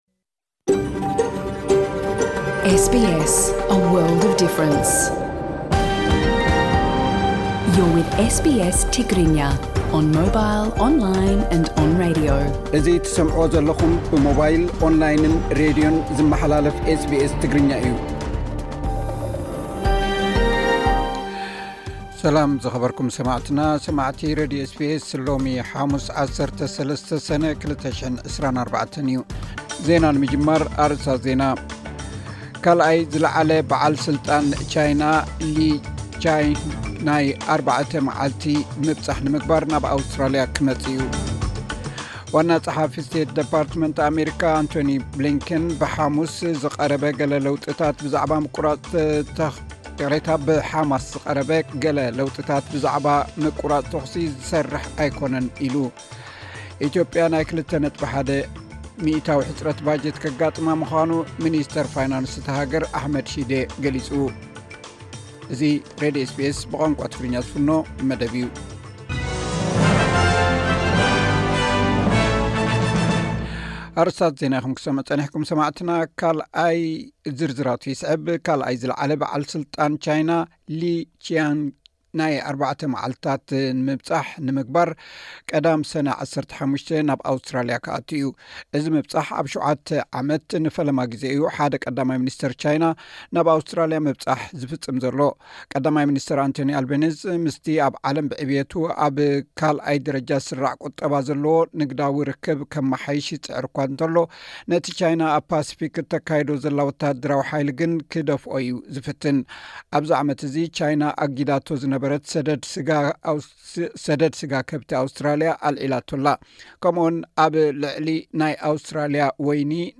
ዕለታዊ ዜና ኤስ ቢ ኤስ ትግርኛ (13 ሰነ 2024)